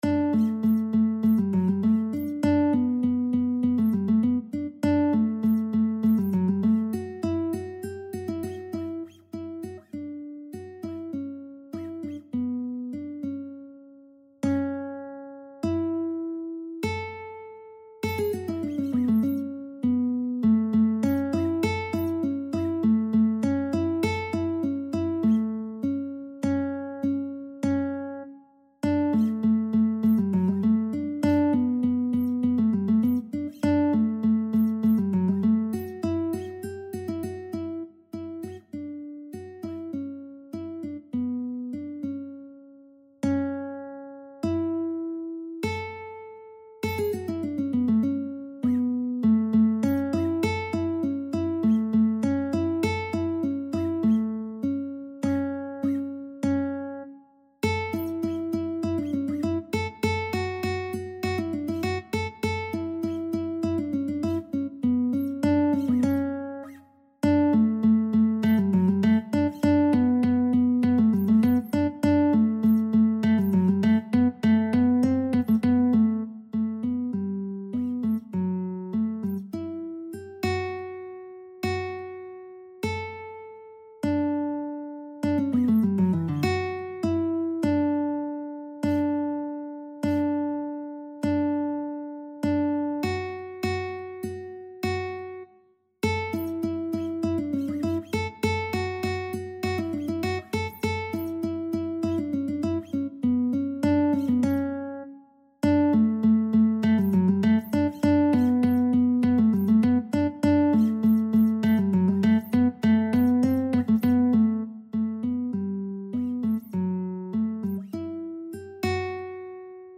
~ = 100 Allegretto
D major (Sounding Pitch) (View more D major Music for Lead Sheets )
2/4 (View more 2/4 Music)
Classical (View more Classical Lead Sheets Music)